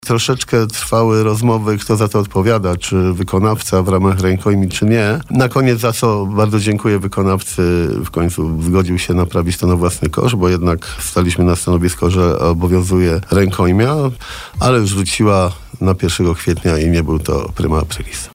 – Trochę czasu zabrały rozmowy na temat tego, kto odpowiada za naprawę: czy wykonawca w ramach rękojmi czy nie. Ostatecznie wykonawca zgodził się naprawić żabę na własny koszt, za co bardzo dziękuję. Żaba wróciła pierwszego kwietnia – i nie był to prima aprilis – mówił dzisiaj na naszej antenie Jarosław Klimaszewski, prezydent miasta.